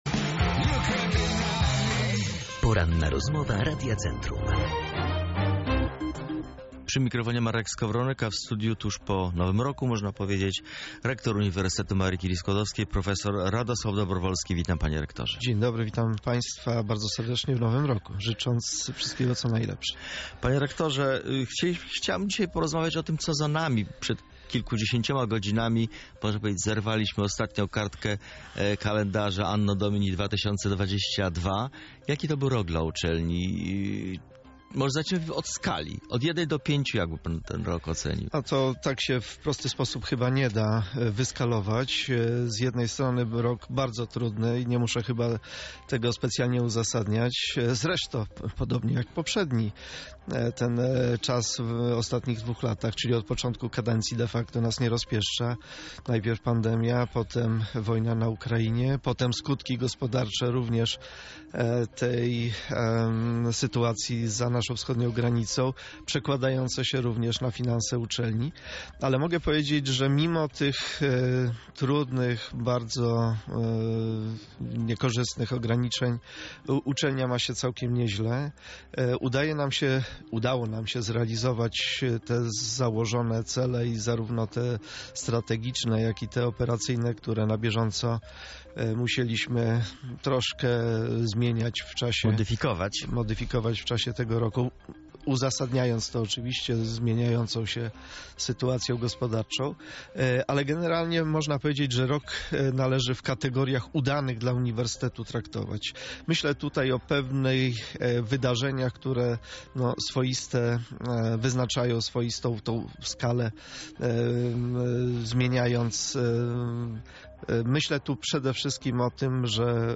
Poranna Rozmowa Radia Centrum
Poranna-Rozmowa-Radia-Centrum.mp3